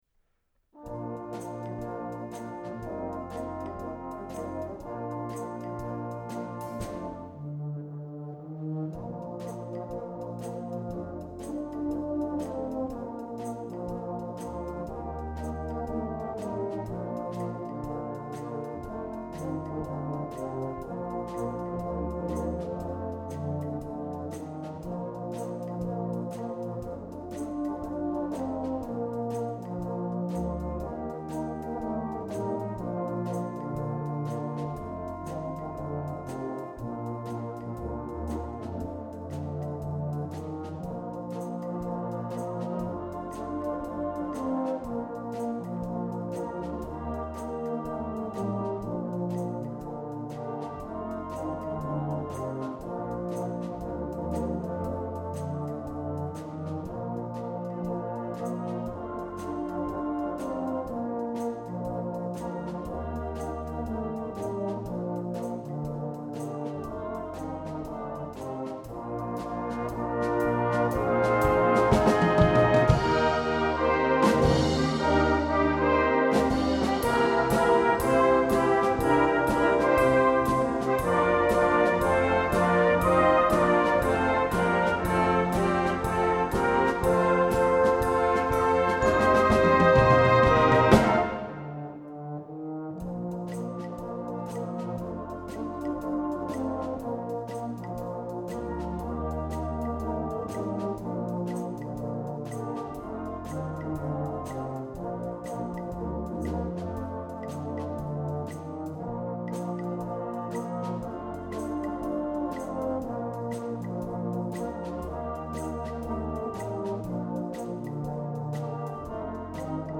Bellshill Salvation Army Band play Any Dream Will Do: